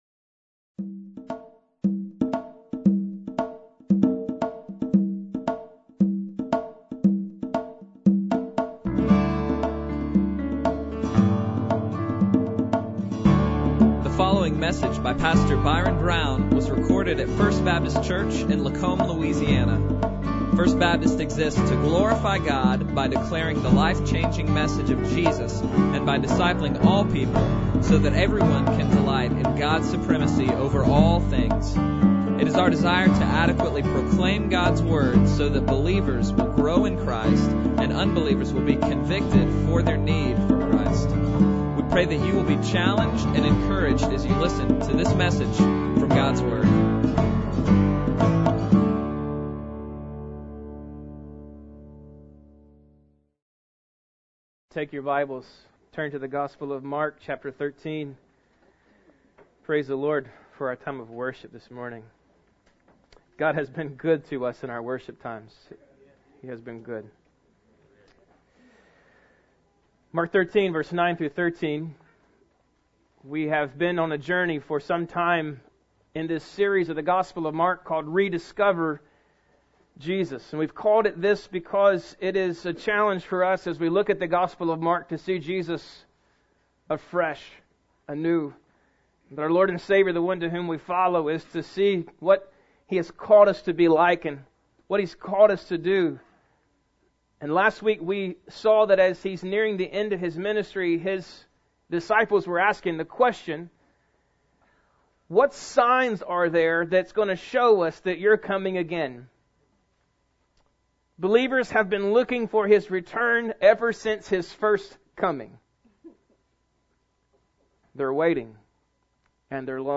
Bible Text: Mark 13:9-13 | Preacher